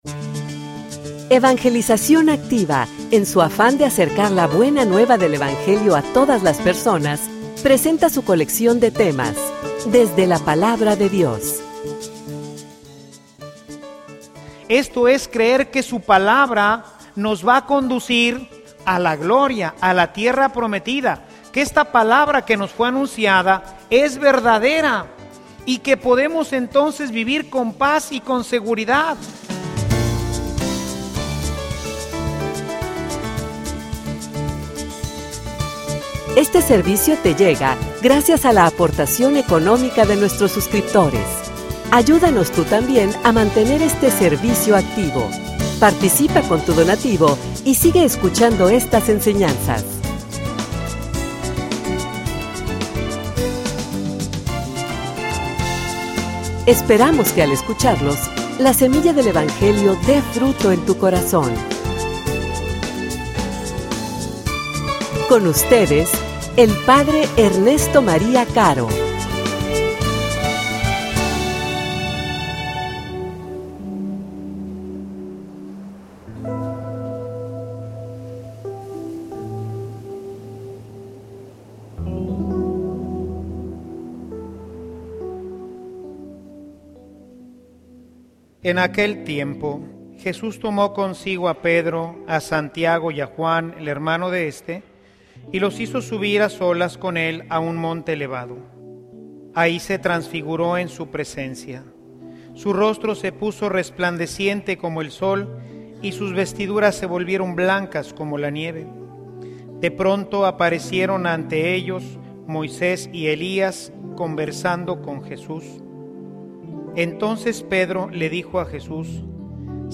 homilia_Un_camino_de_fe_y_esperanza.mp3